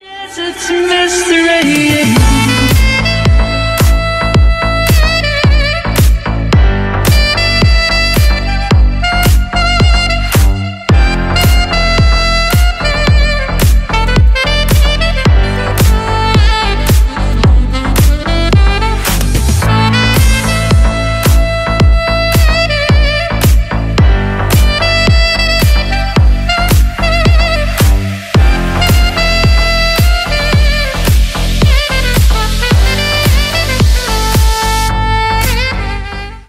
Ремикс # Танцевальные